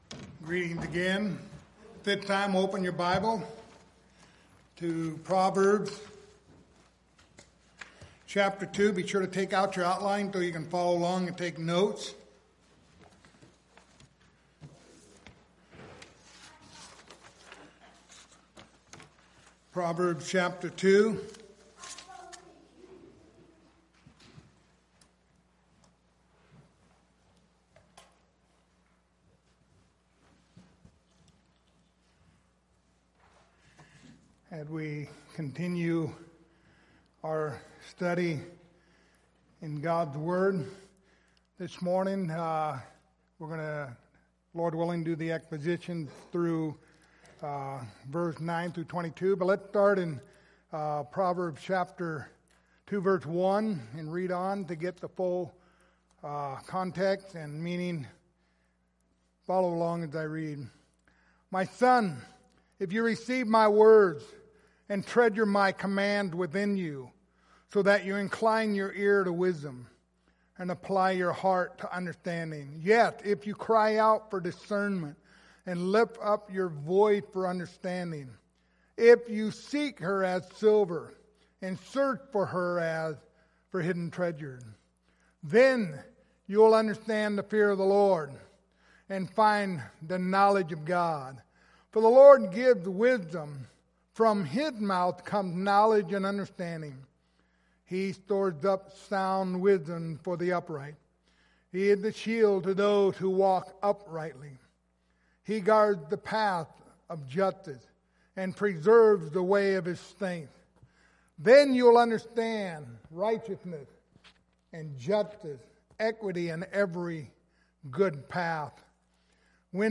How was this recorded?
The Book of Proverbs Passage: Proverbs 2:9-19 Service Type: Sunday Morning Topics